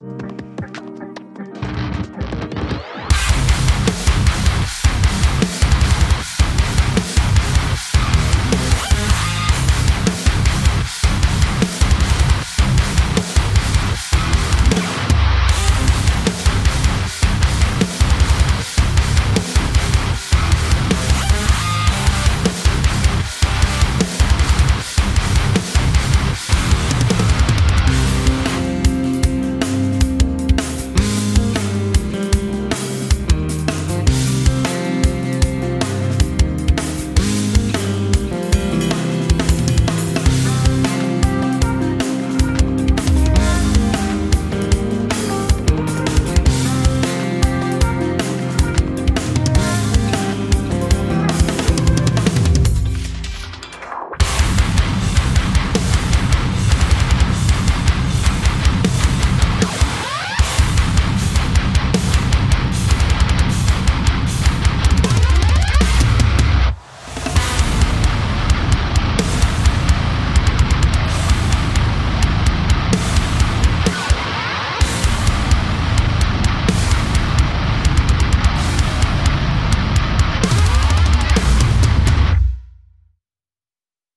Описание: Ударная установка